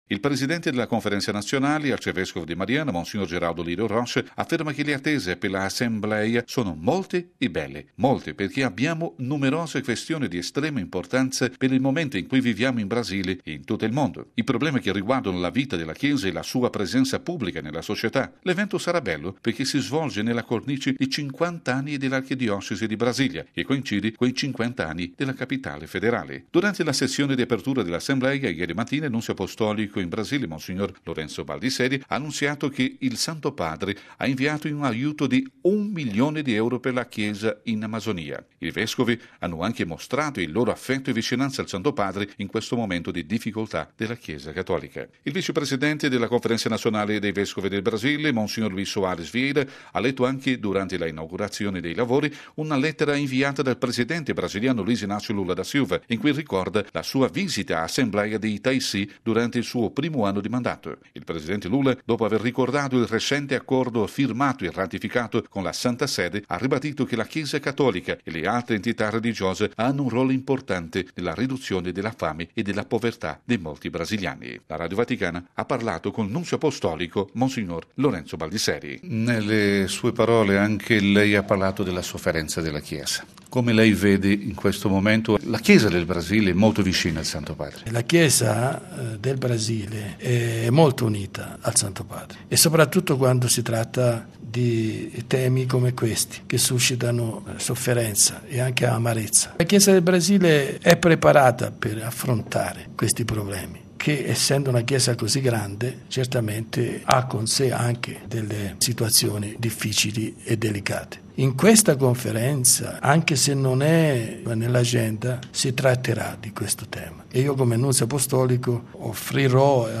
Inaugurata la 48.ma plenaria dei vescovi brasiliani. Intervista con il nunzio Lorenzo Baldisseri